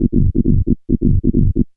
Index of /90_sSampleCDs/Club_Techno/Bass Loops
BASS_135_G.wav